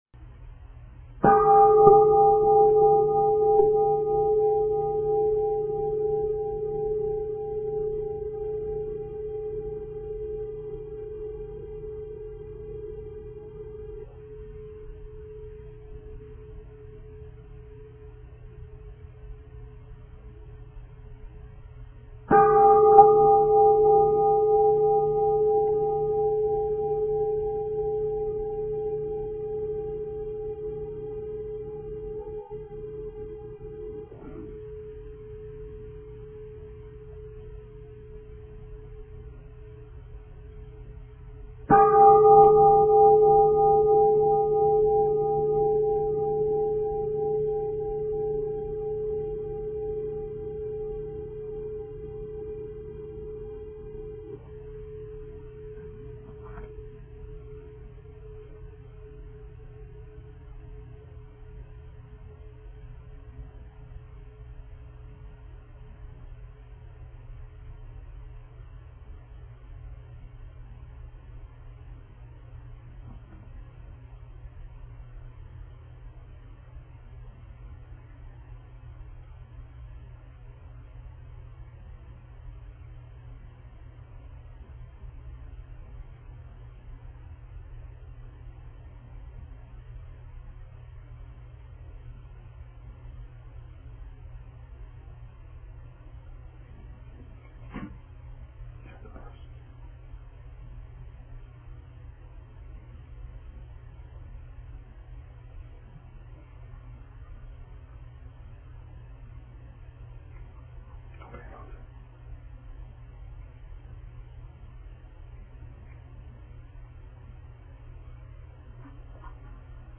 Four-day Retreat | January 2011